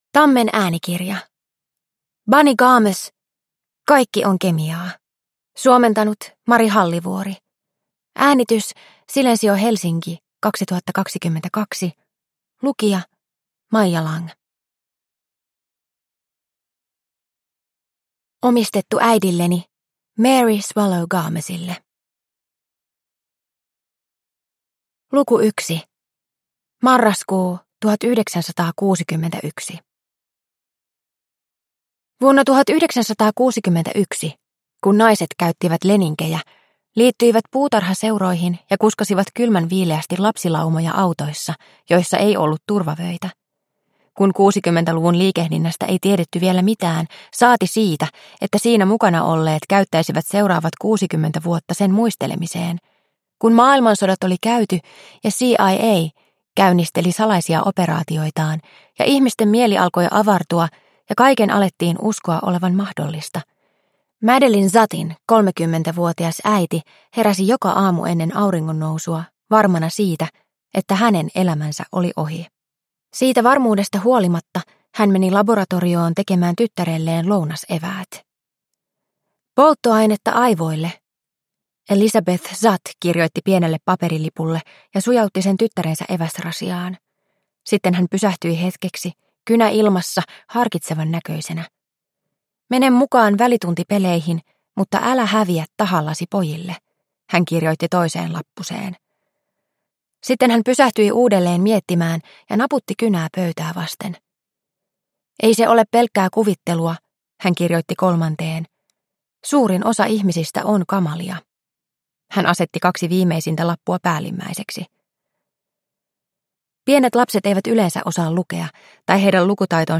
Kaikki on kemiaa – Ljudbok – Laddas ner